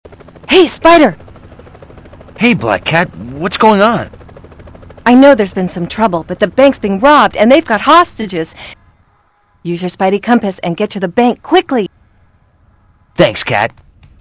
She also has a different voice actor in the demo.
demo voice] [final voice]